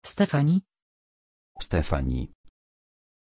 Pronunciation of Stéphanie
pronunciation-stéphanie-pl.mp3